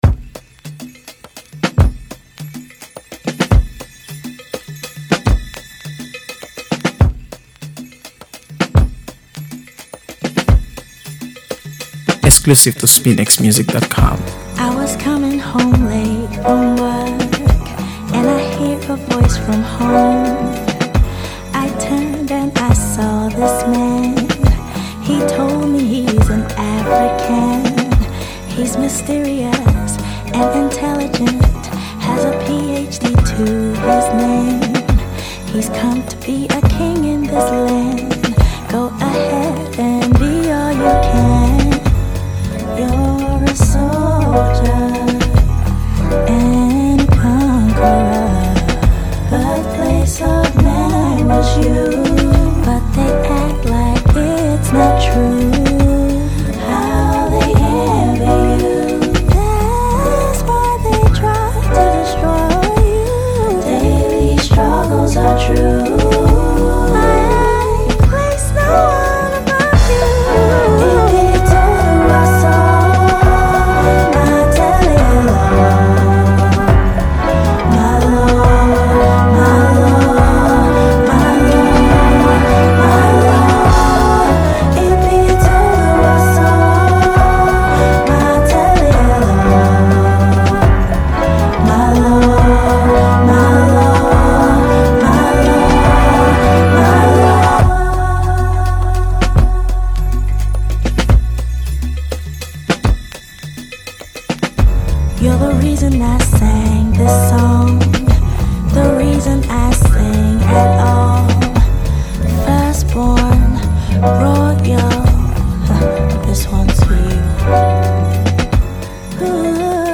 AfroBeats | AfroBeats songs
Nigerian R&B
If you’re a fan of smooth R&B with a touch of Afrobeat charm